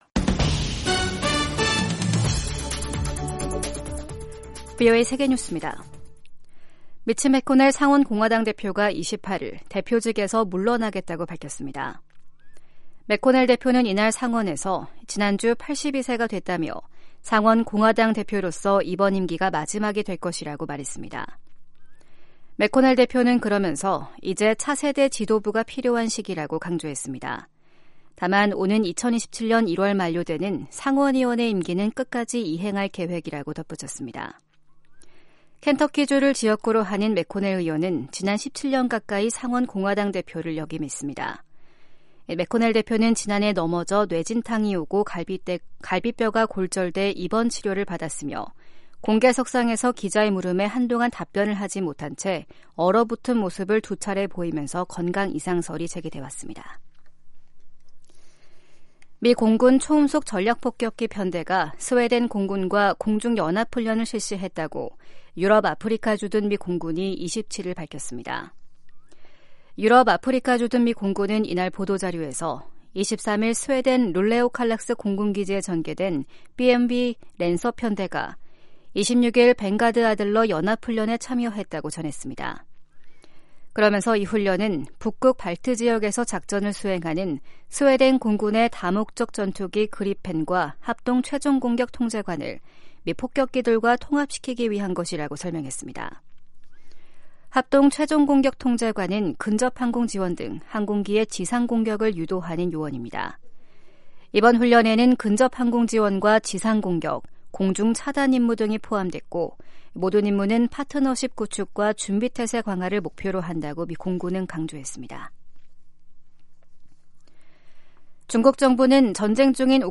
세계 뉴스와 함께 미국의 모든 것을 소개하는 '생방송 여기는 워싱턴입니다', 2024년 2월 29일 아침 방송입니다. '지구촌 오늘'에서는 미국과 영국·독일 등 북대서양조약기구(NATO·나토) 동맹국들이 우크라이나 파병 제안을 거부한 소식 전해드리고, '아메리카 나우'에서는 조 바이든 대통령과 도널드 트럼프 전 대통령이 미시간주 경선에서 각각 승리한 이야기 살펴보겠습니다.